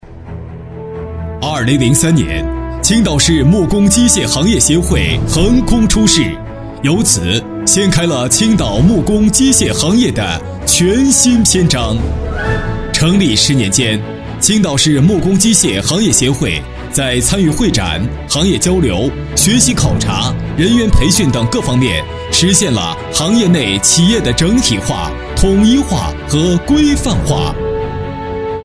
男35实惠年轻-纵声配音网
男35 青岛木工协会（大气）.mp3